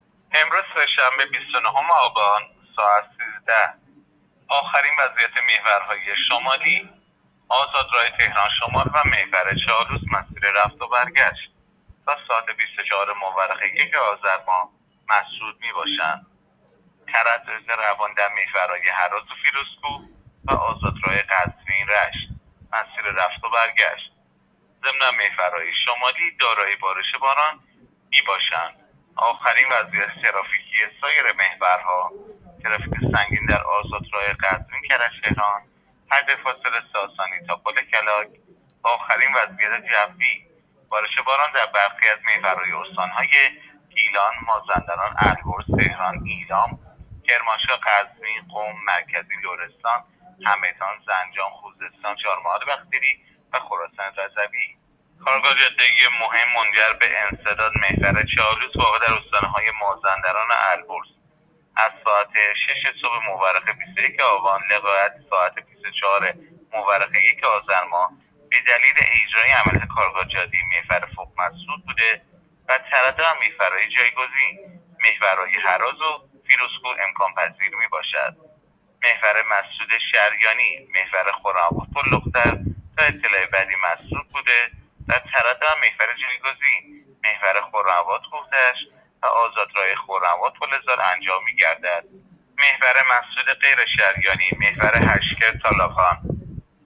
گزارش رادیو اینترنتی از آخرین وضعیت ترافیکی جاده‌ها تا ساعت ۱۳ روز ۲۹ آبان؛